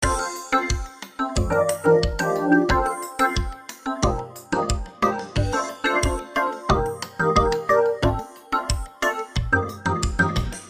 罗德斯博萨Gm 90
描述：鼓和罗德斯钢琴在音频逻辑中录制和重新混合
Tag: 90 bpm Chill Out Loops Groove Loops 1.80 MB wav Key : Unknown